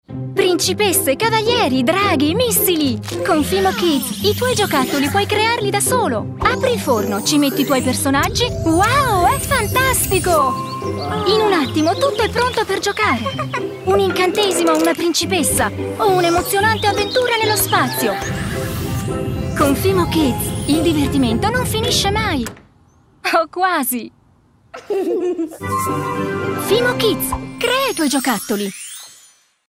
Sprechprobe: eLearning (Muttersprache):
I am an educated actress and I speak with a broad italian accent, what makes my speach very clear and articulately.
I have a joung, beautiful and light voice.